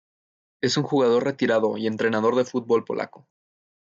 re‧ti‧ra‧do
/retiˈɾado/